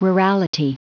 Prononciation du mot rurality en anglais (fichier audio)
Prononciation du mot : rurality